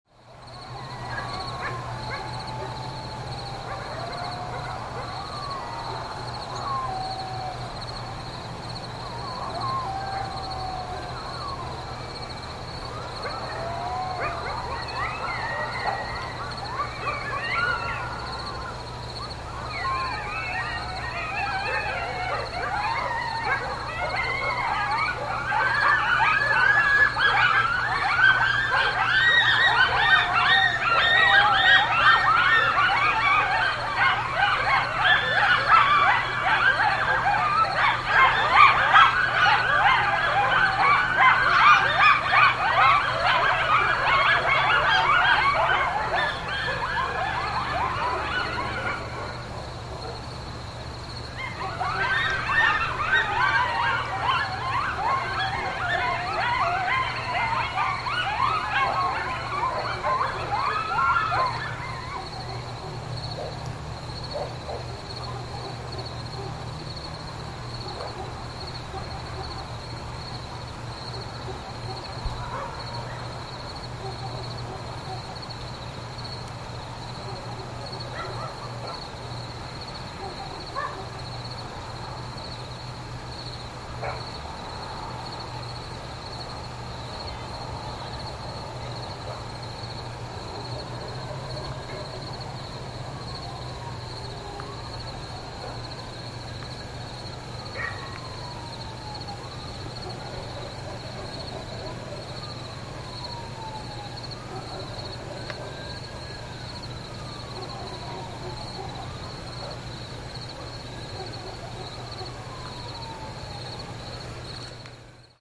Coyotes With Night Ambiance Bouton sonore